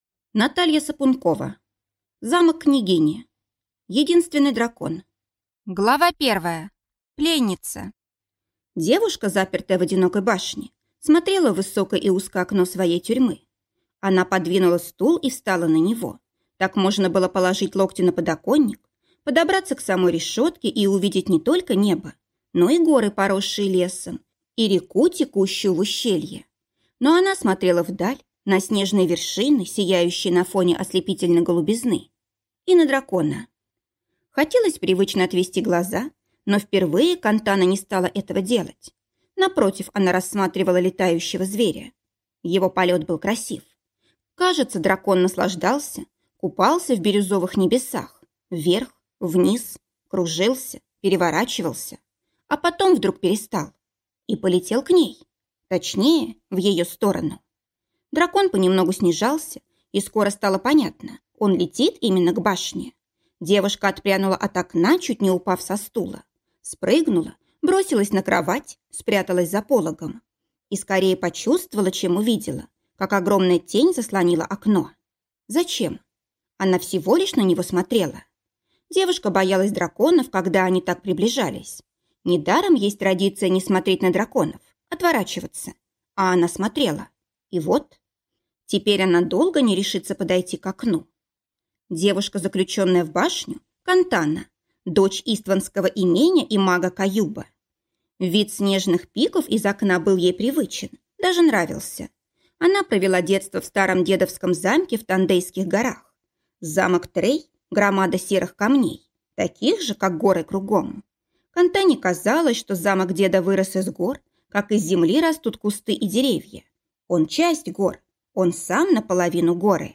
Аудиокнига Замок княгини | Библиотека аудиокниг